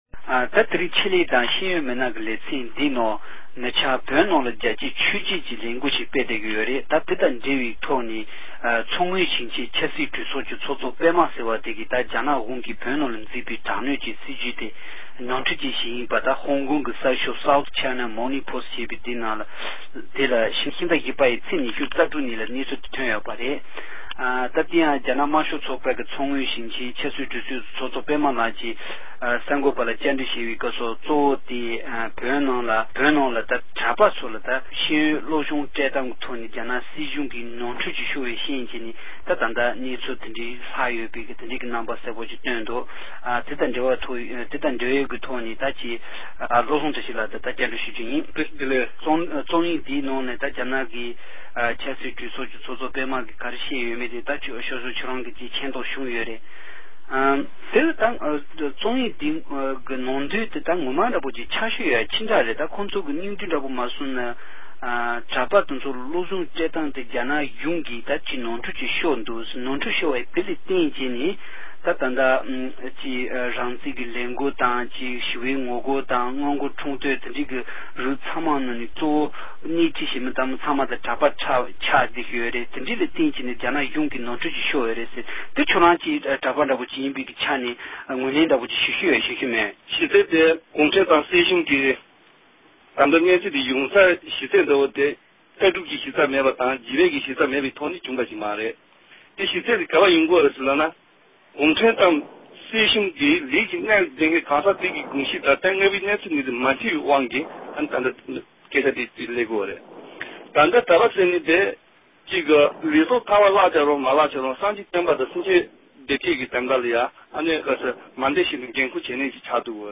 བཅར་འདྲིས